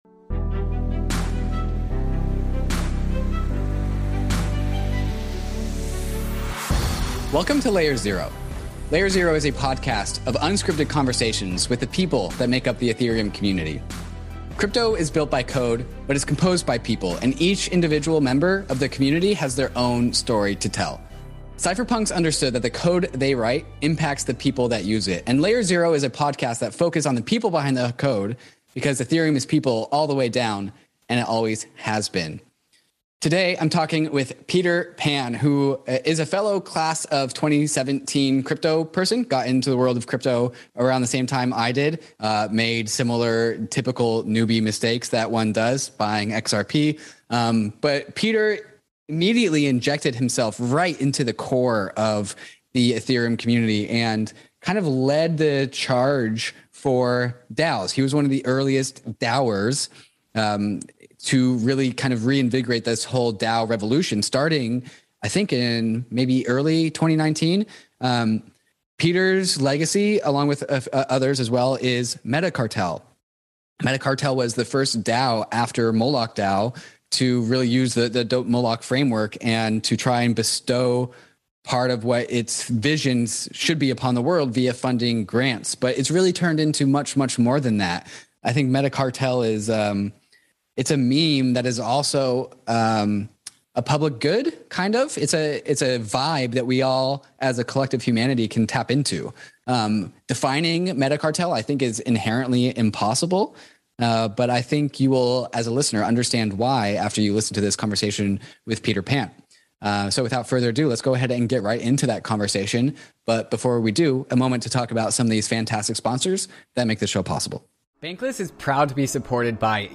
This conversation revolves around the power of curating people and projects.